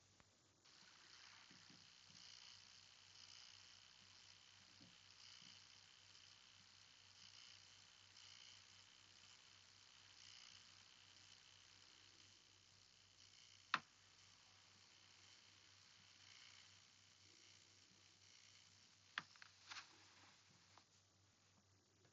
Mainboard oder CPU brutzeln/brummen
Dark Power 13 850W SSD: Samsung 990 Pro 1TB und Crucial P3 Plus 4TB Wenn ich den PC starte und in Windows 11 boote, dann kommt irgendwo vom Mainboard ein brutzelndes oder brummendes Geräusch ( siehe Anhang ) was nicht normal klingt.
(Am Handy laut und am Ohr kann ich die mp3 im Anhang sehr gut hören. Am PC abgespielt wird bei mir das Geräusch unterdrückt) Ich habe bis auf Mainboard, CPU, M.2 SSDs und RAM zum Testen nichts angeschlossen.
Lösung siehe Post #27 Anhänge brummen.mp3 868,3 KB